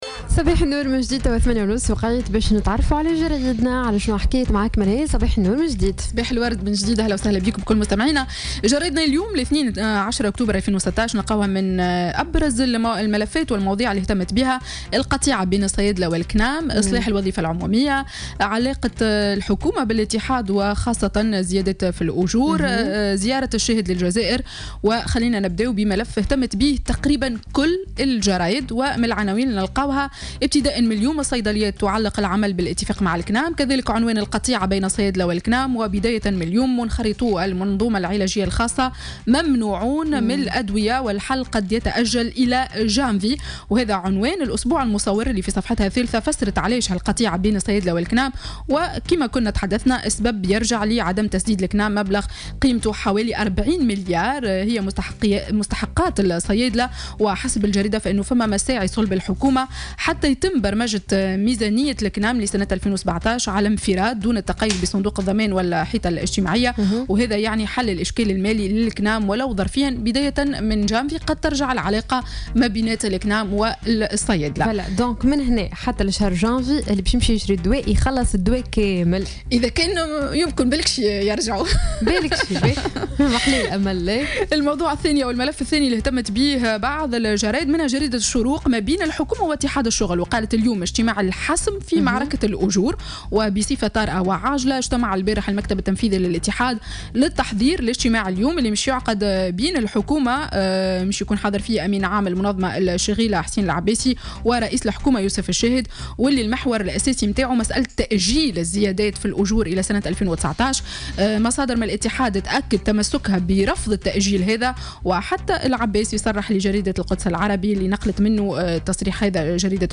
Revue de presse du lundi 10 Octobre 2016